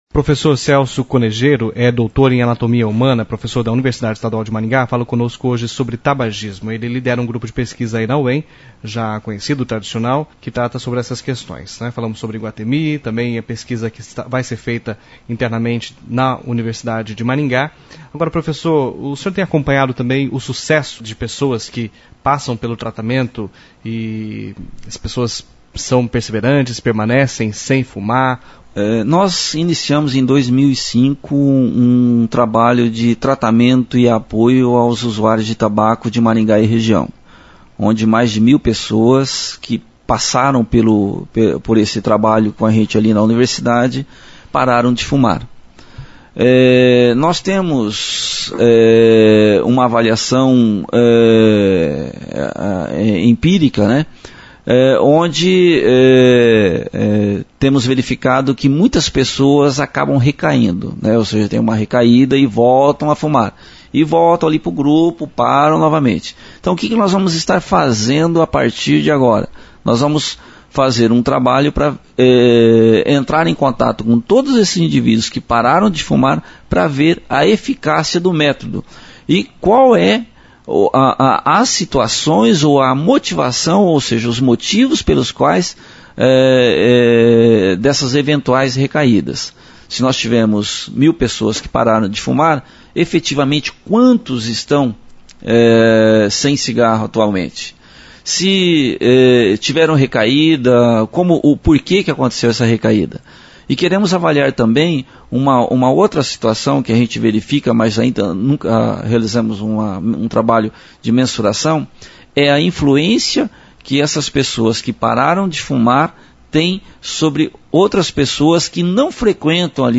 entrevista-cbn-parte-3